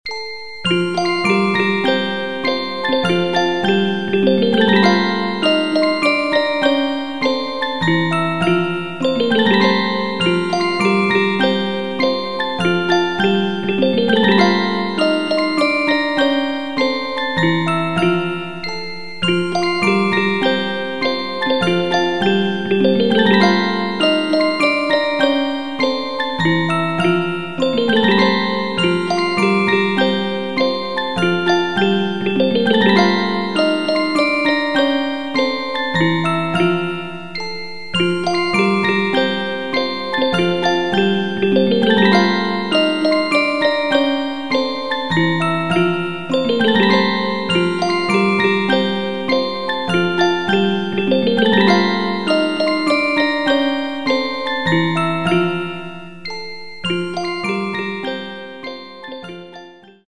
알림음(효과음) + 벨소리
알림음 8_랄랄라랄랄라.mp3